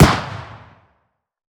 CookoffSounds / shotshell / mid_3.wav
Cookoff - Improve ammo detonation sounds